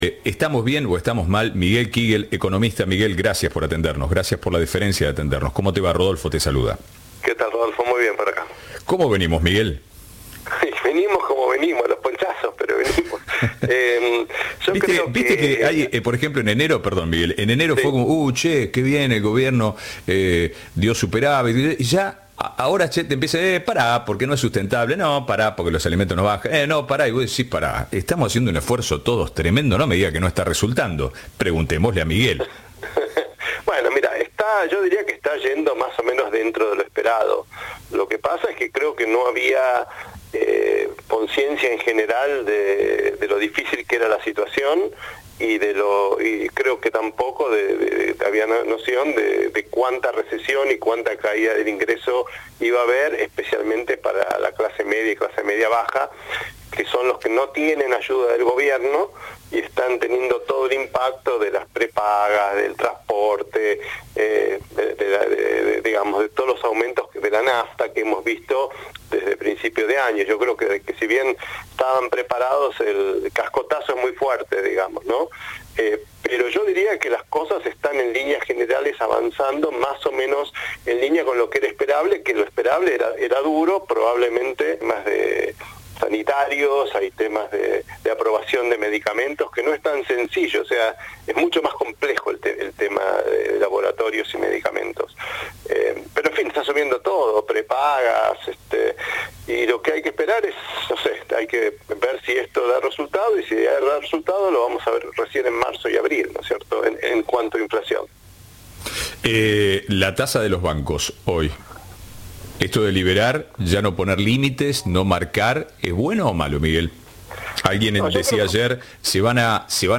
El economista analizó las últimas medidas de Javier Milei y su impacto en la sociedad. Escuchá la entrevista de Ahora País.